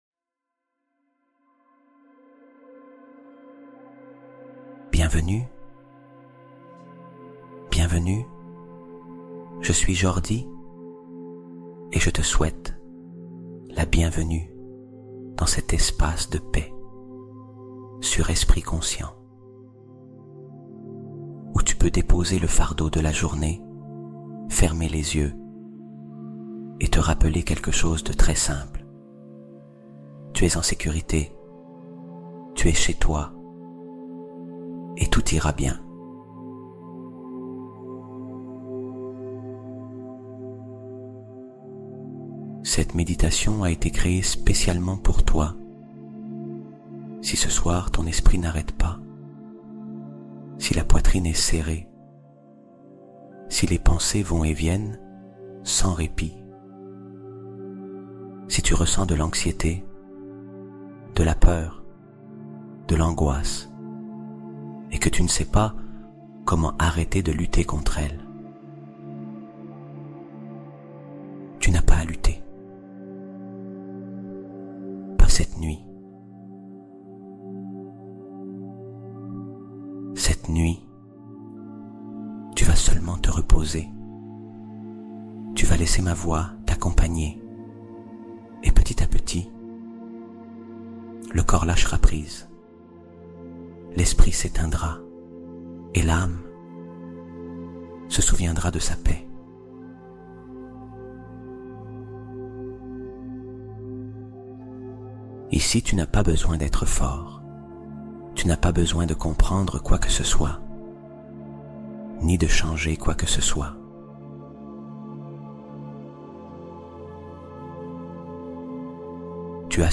Paix Sommeil : Méditation nocturne pour apaiser l'agitation mentale